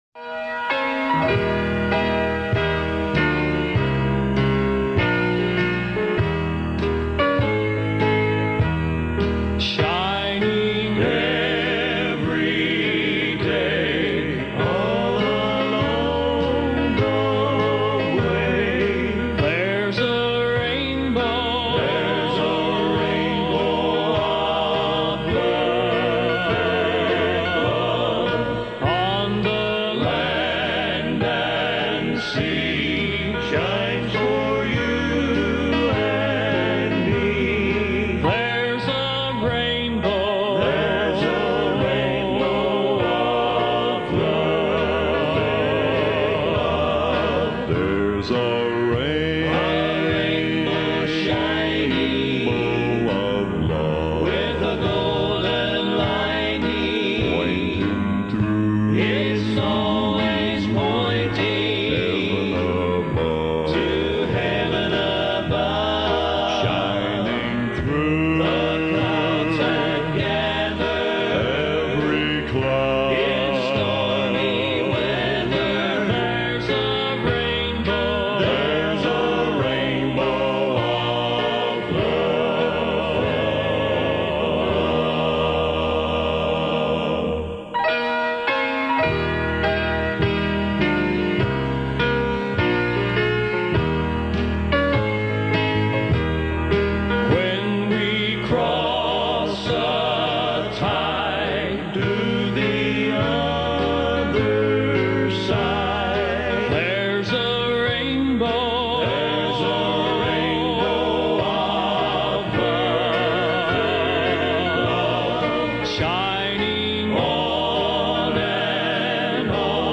baixo absoluto
gospel
voz grave